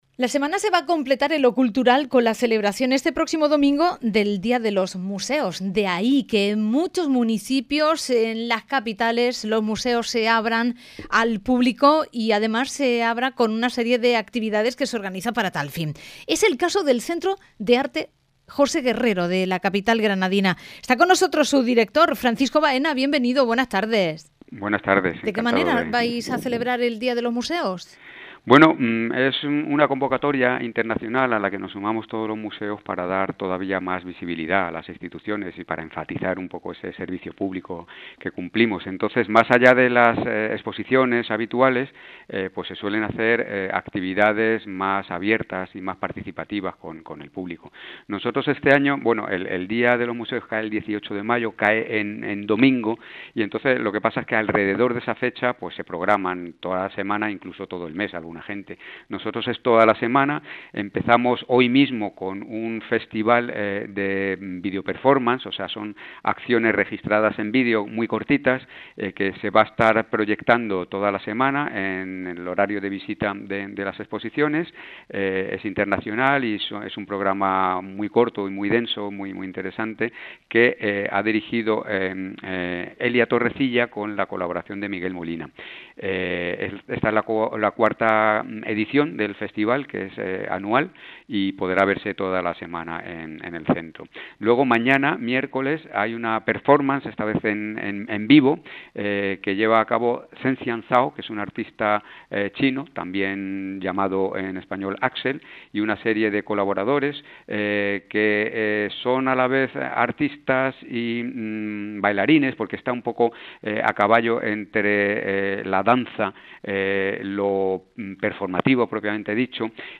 ENTREVISTA
la-casa-rosa-discografica-veiculo-longo-entrevista-cadena-ser.mp3